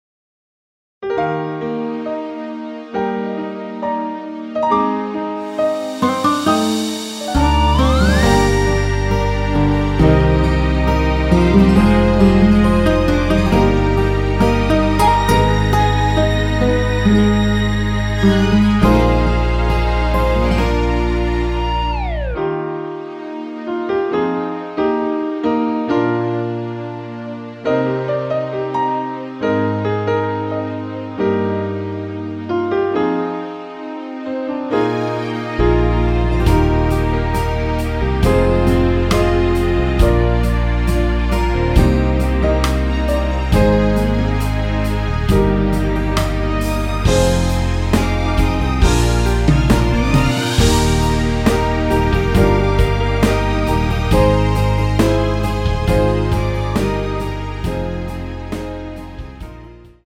여자키에서(-2) 더 내린 MR입니다.(미리듣기 참조)
Db
앞부분30초, 뒷부분30초씩 편집해서 올려 드리고 있습니다.
중간에 음이 끈어지고 다시 나오는 이유는